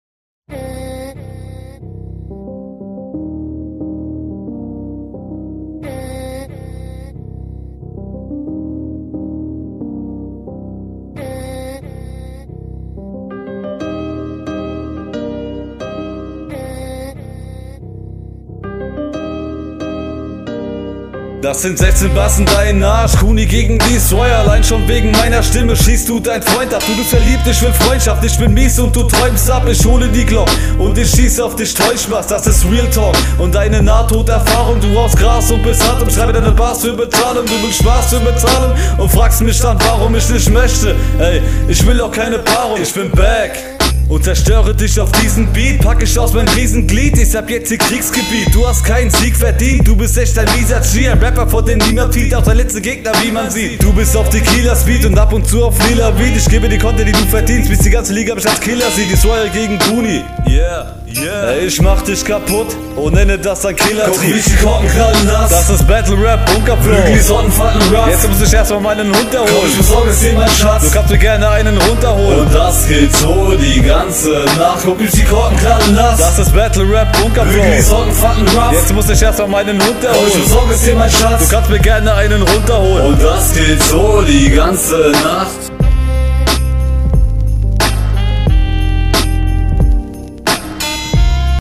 Kommst auf jeden Fall schon mal sehr mächtig auf dem Beat, der Stimmeinsatz ist sehr …
Soundqualität: Nicht schlecht, hat man in der Train definitiv schlechter gehört.
Der beat gefällt mir sehr düster .